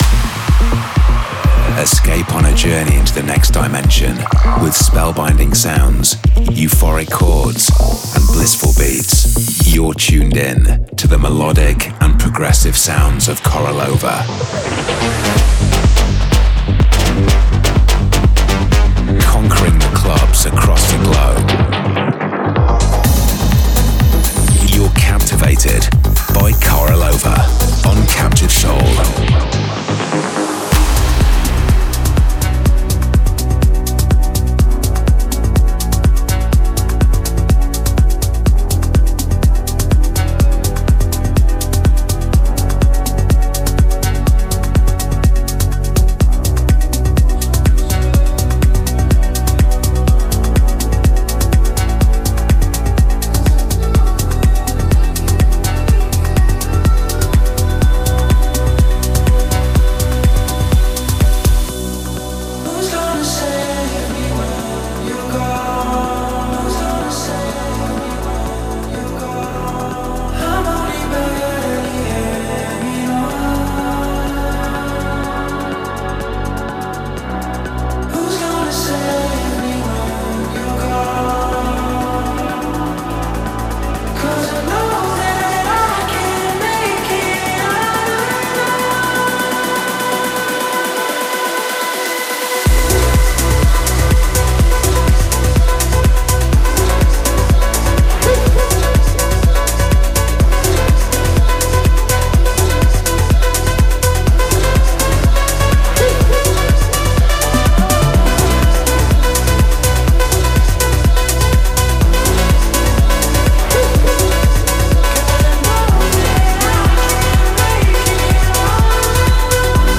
The bi-weekly radio show